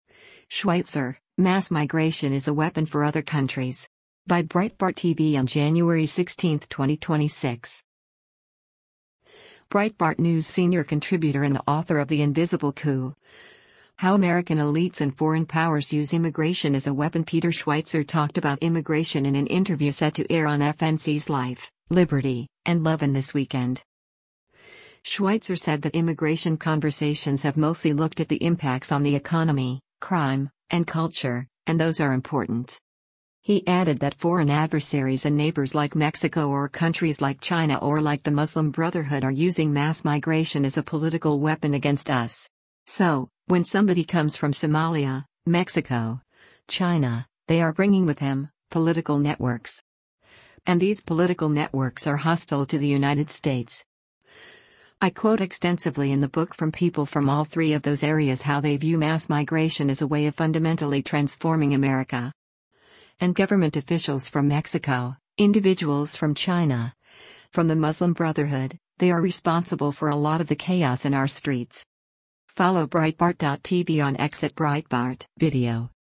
Breitbart News Senior Contributor and author of The Invisible Coup: How American Elites and Foreign Powers Use Immigration as a Weapon Peter Schweizer talked about immigration in an interview set to air on FNC’s “Life, Liberty, and Levin” this weekend.